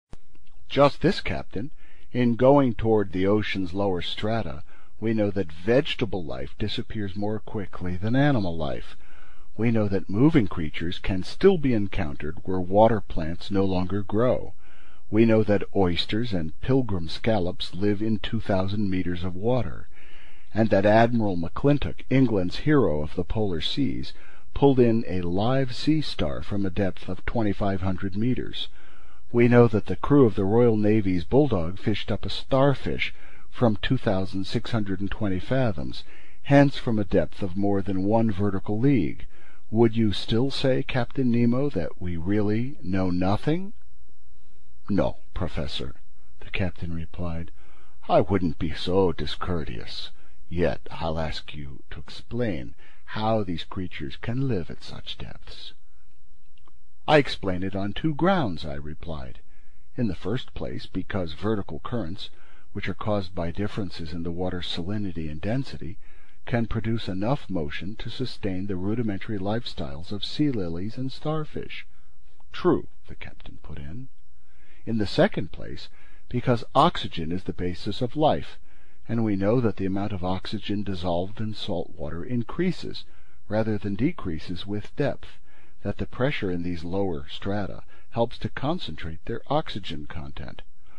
英语听书《海底两万里》第416期 第25章 地中海四十八小时(43) 听力文件下载—在线英语听力室